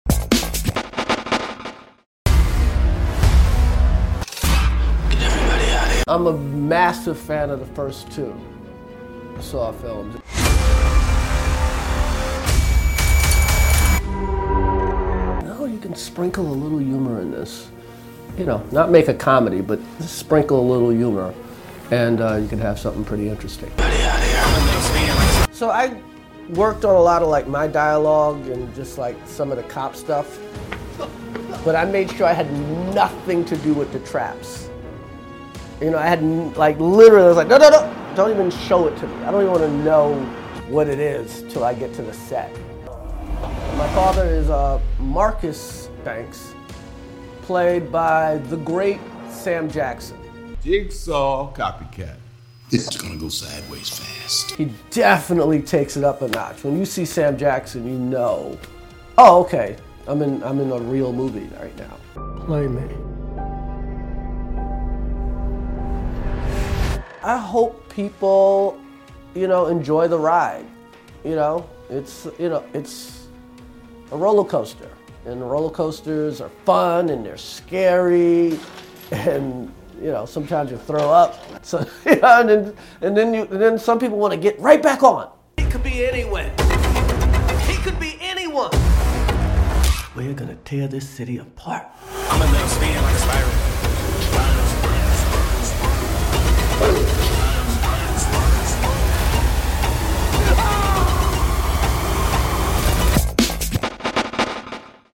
Spiral: From the Book of Saw is the 9th installment in the Saw franchise, and this time, star an executive producer, Chris Rock is putting his comedic twist on the thriller. The actor sat down to tell us about his approach to taking on the scary genre and what it was like play...